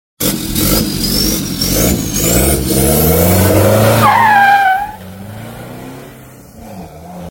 Another D4d with some straight pipe and stage 2 tune action!